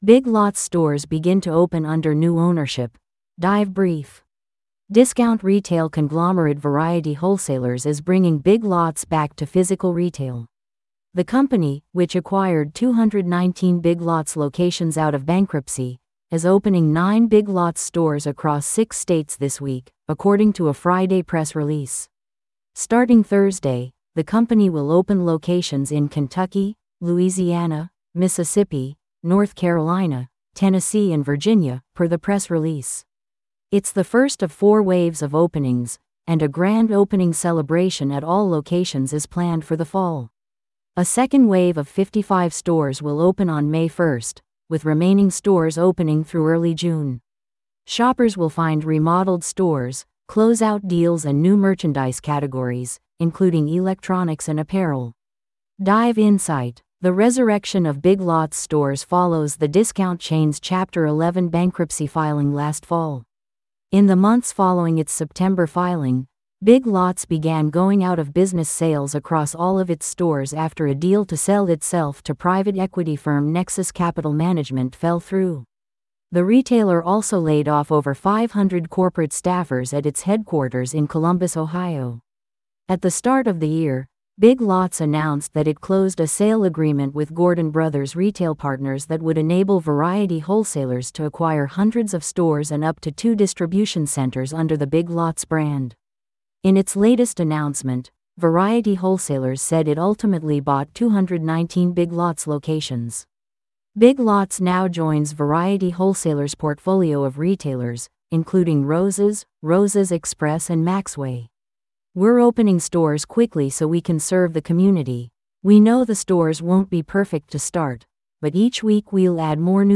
This audio is auto-generated.